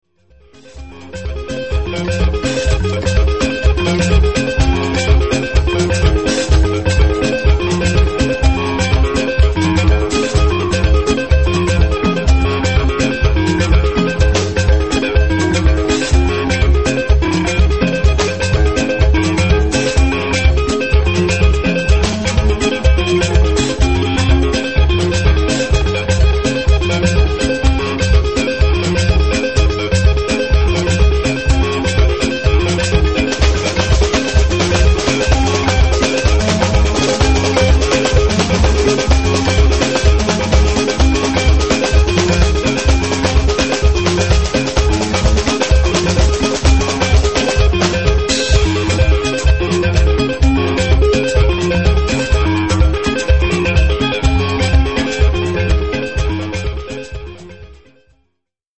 Niezwykła to muzyka i niezwykłe instrumentarium.
Potem zmiksował je w hotelowym pokoju.